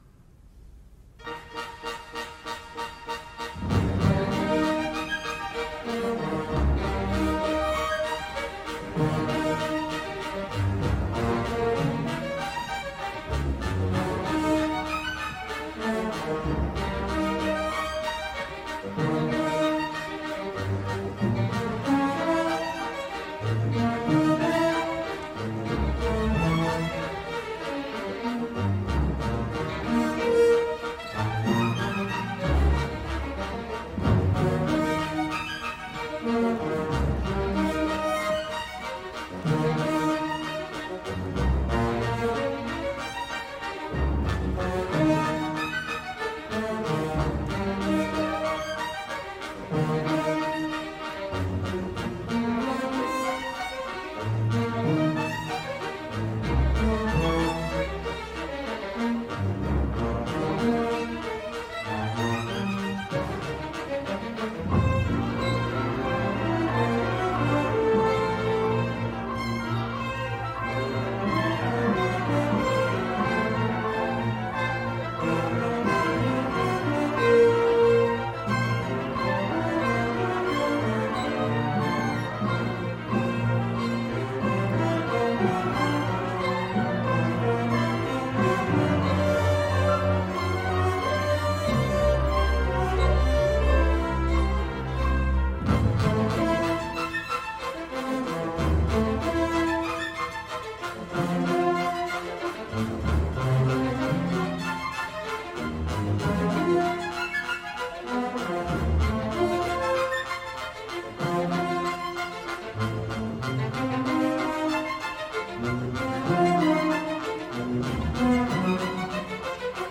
L’interludi orquestral